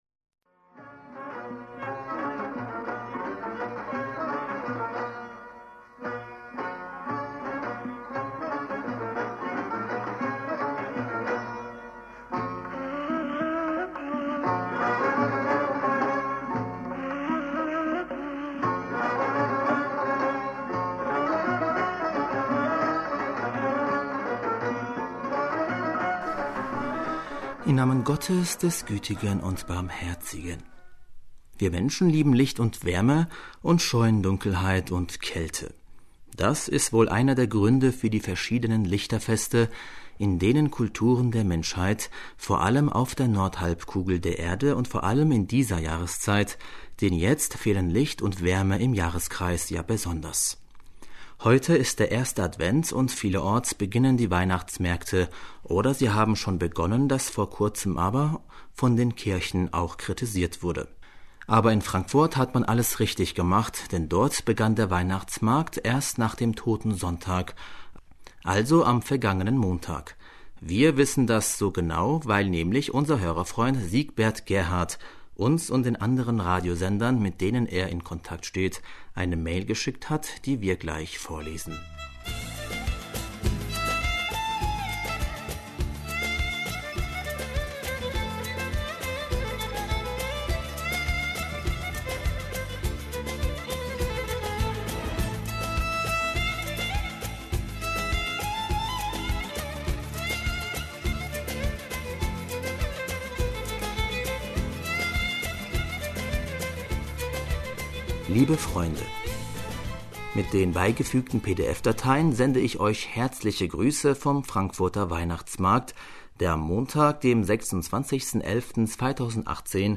Hörerpostsendung am 2. Dezember 2018 - Bismillaher rahmaner rahim - Wir Menschen lieben Licht und Wärme und scheuen Dunkelheit und Kälte.